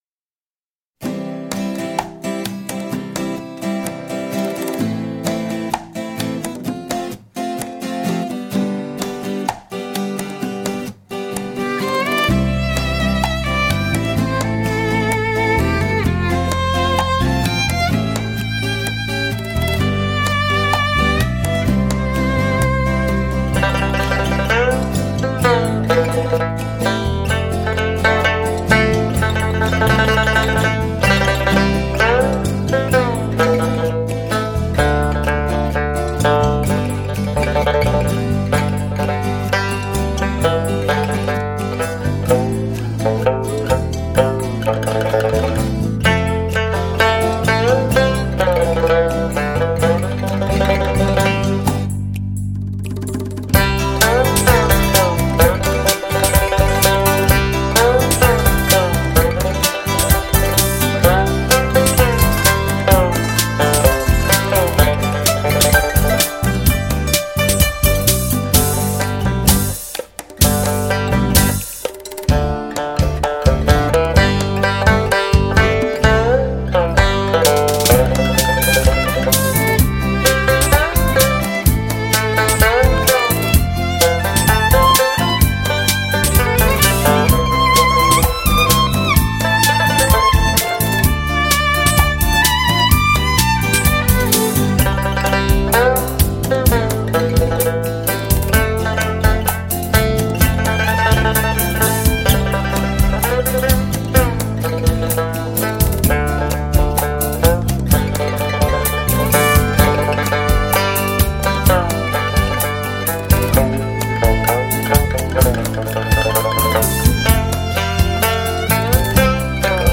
录音棚：乐海录音棚
小提琴
吉他
黑管
长笛
竹笛
双簧管
二胡
采用最新科技·开创革命性的K2HD全方位环绕